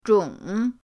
zhong3.mp3